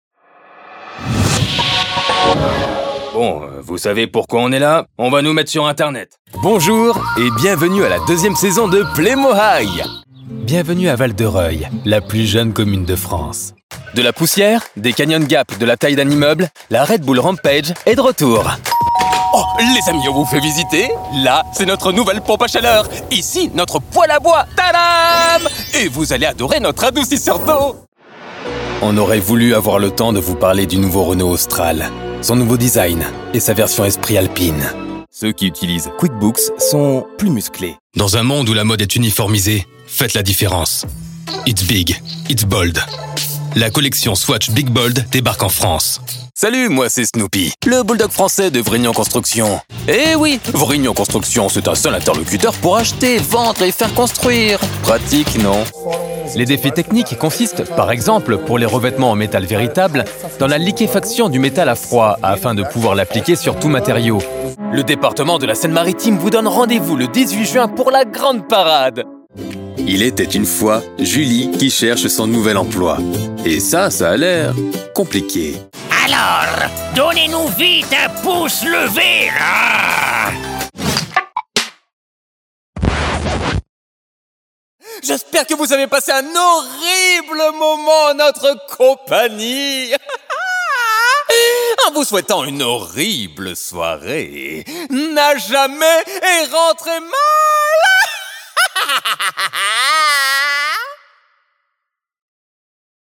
Voix Off HommeProfessionnelFrançais
Une voix naturelle, percutante ou incarnée selon vos besoins, avec une palette qui s’adapte à tous les formats : Publicité Narration Motion design Doublage & personnages E-learning & corporate
3 octaves de tessiture = une large gamme de voix adaptées à votre message
Home studio professionnel avec équipement haut de gamme